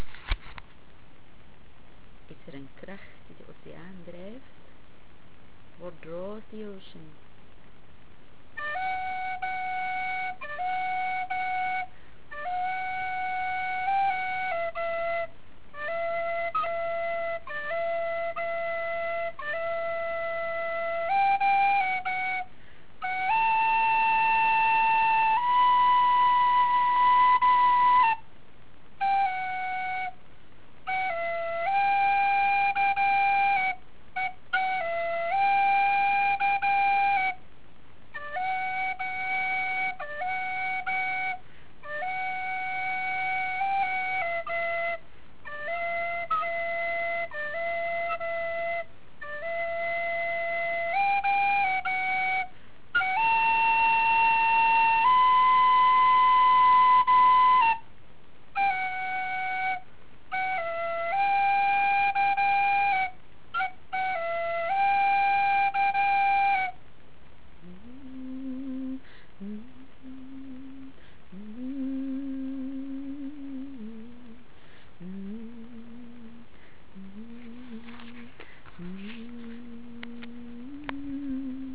oceaan.wav (322 KB)